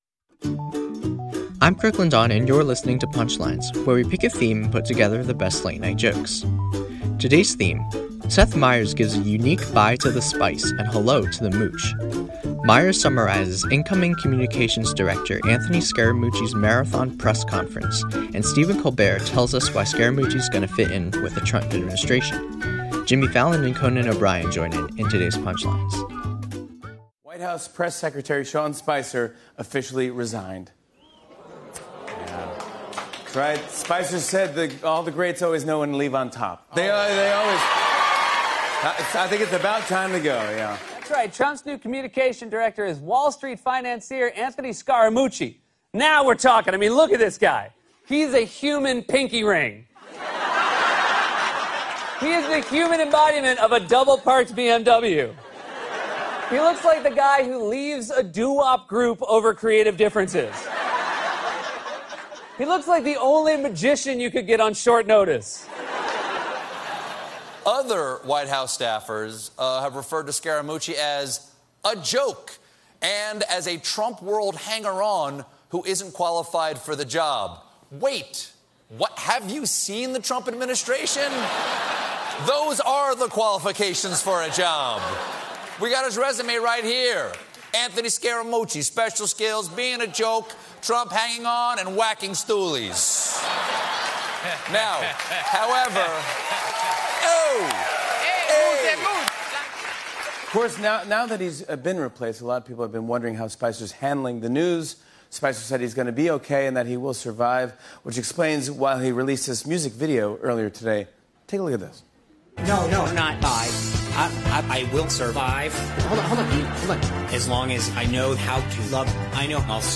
The late-night comics take a look at the new White House communications director.